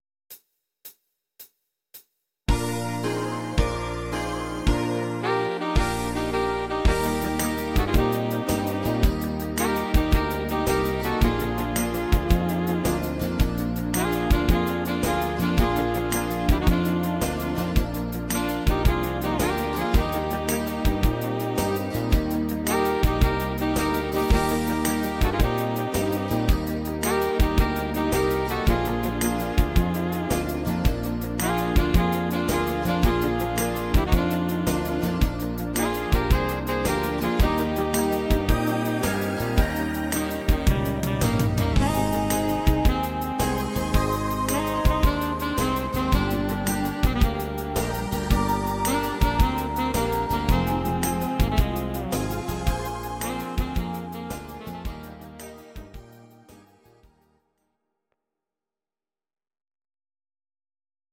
Please note: no vocals and no karaoke included.
Your-Mix: Instrumental (2073)